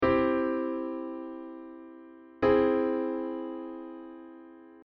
Aug-Chord-2
Aug-Chord-2.mp3